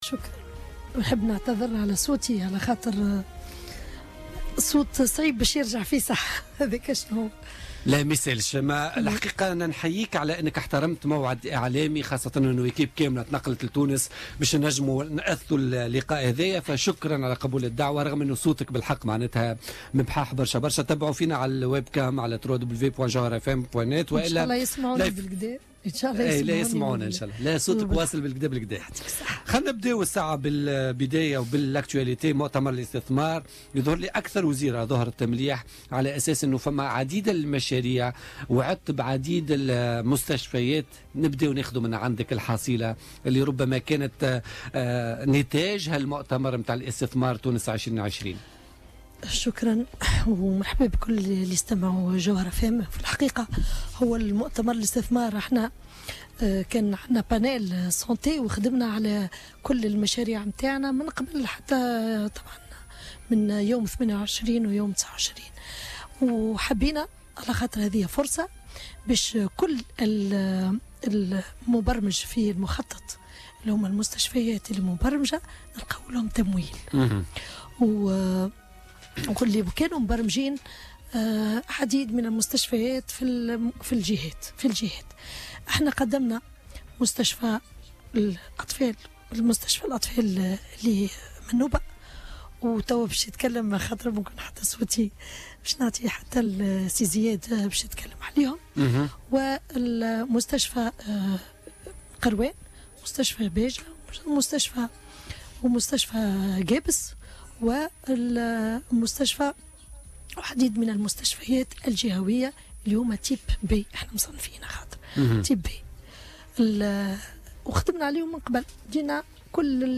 قالت وزيرة الصحة سميرة مرعي ضيفة بولتيكيا اليوم الإثنين 5 ديسمبر 2016 إن مؤتمر الاستثمار كان فرصة لوزارتها لتقدم المشاريع التي أعدت لها.